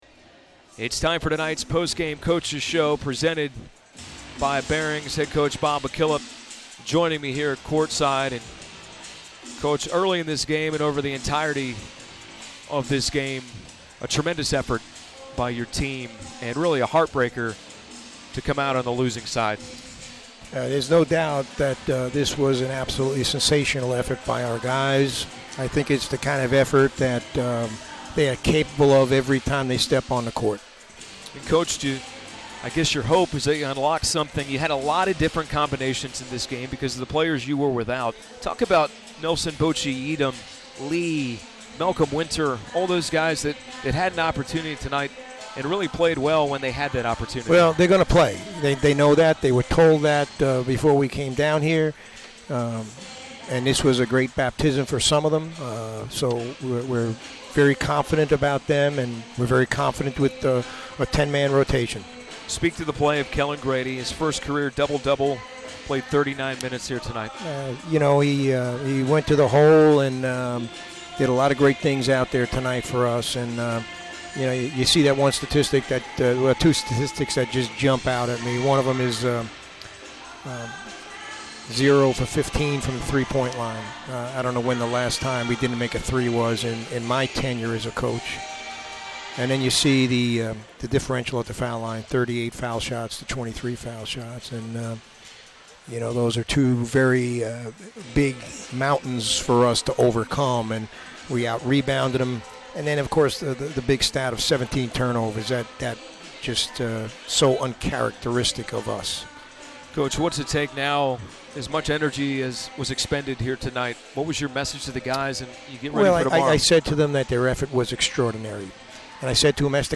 McKillop Postgame Interview
McKillop Postgame MU.mp3